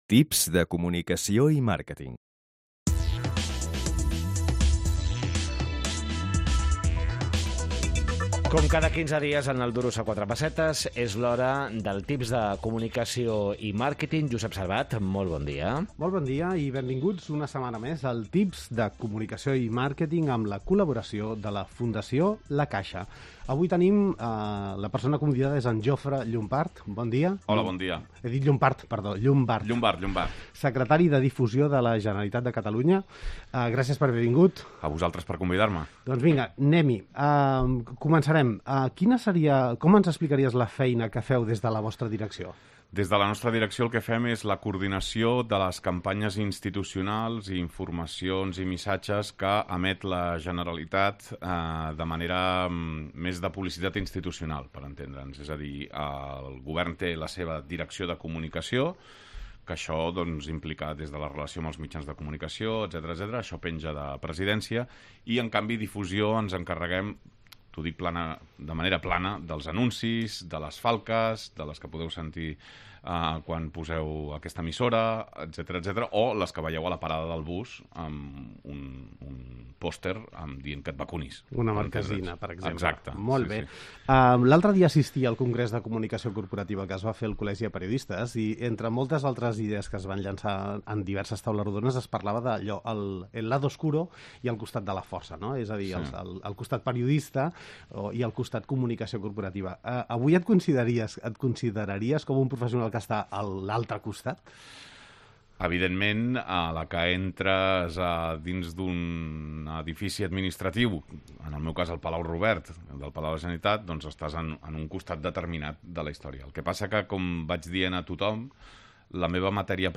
Entrevista a Jofre Llombart, Secretari de Difusió de la Generalitat de Catalunya